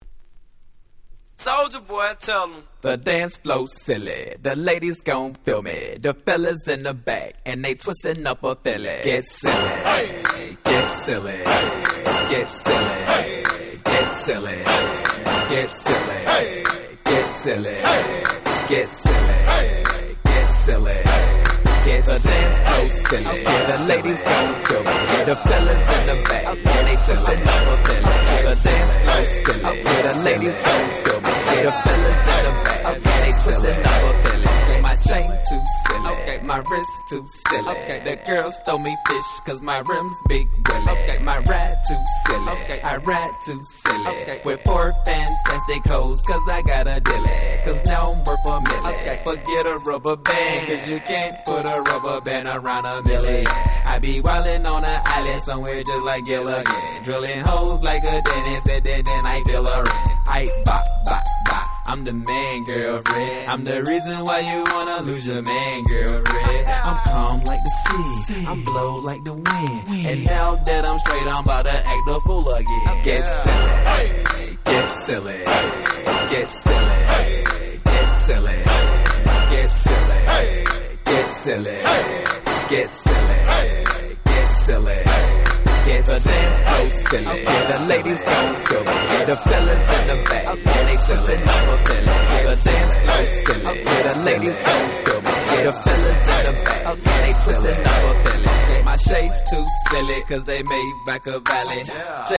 07' Super Hit Southern Hip Hop !!